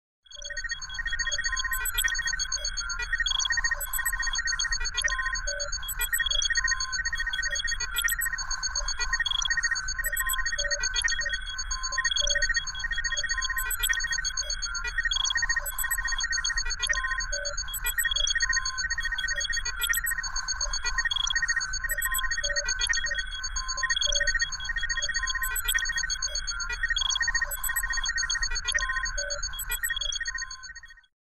Звуки космоса
Центр управления космической станцией